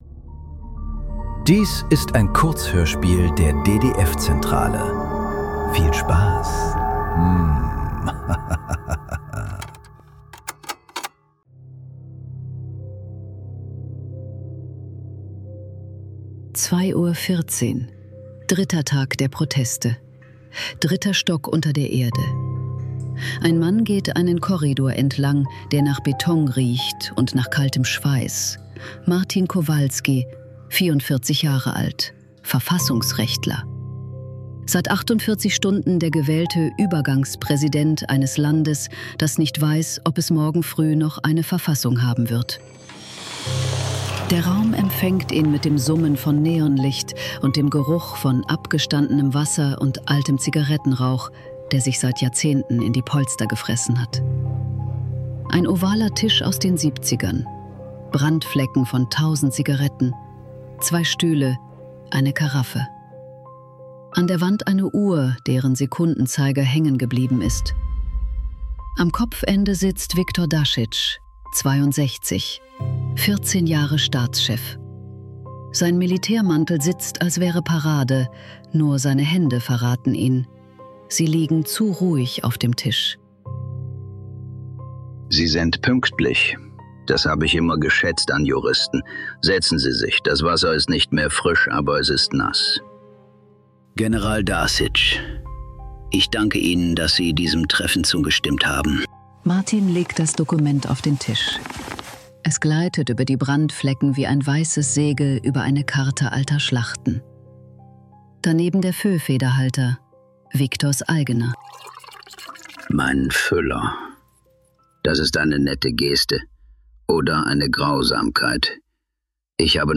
Statik ~ Nachklang. Kurzhörspiele.